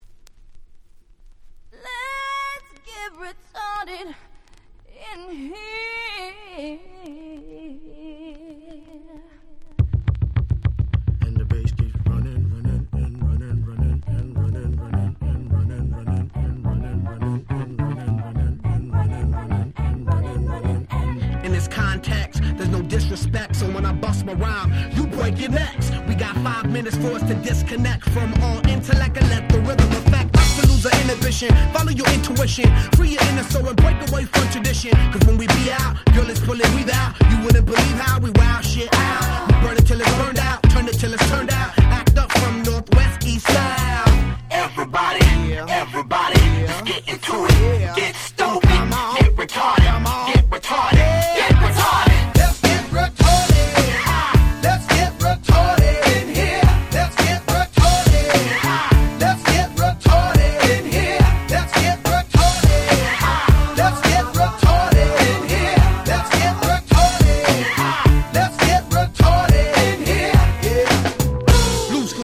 03' Super Hit Hip Hop / R&B !!